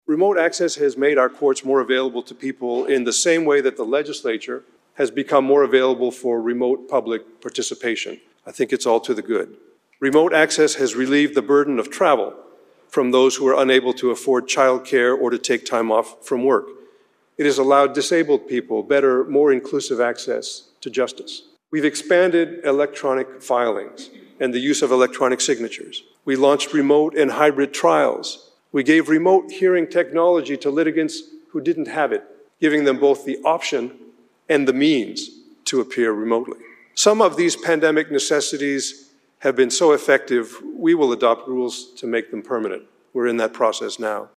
WA Supreme Court Chief Justice González Delivers State of the Judiciary Address (Listen/Watch)